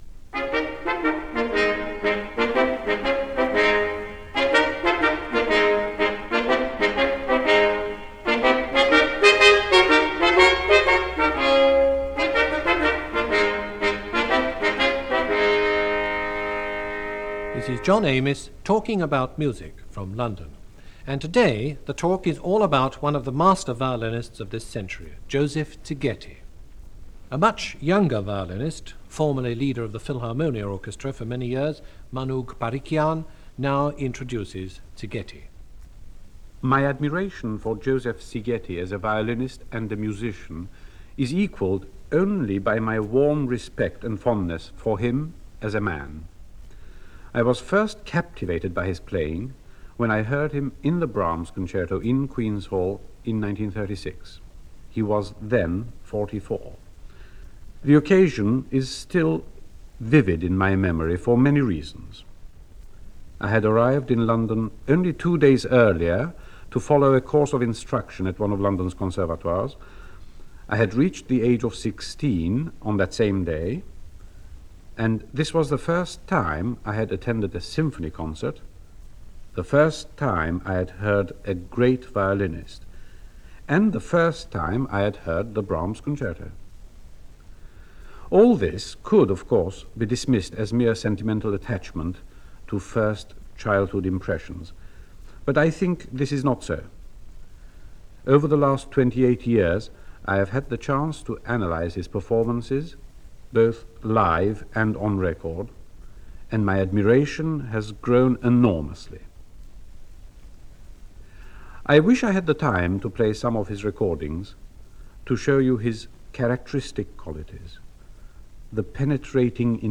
The legendary violinist Joseph Szigeti, in conversation with Manoug Parikian for the BBC Radio series Talking About Music – from circa 1964.
BBC-Joseph-Szigeti-Interview-1964.mp3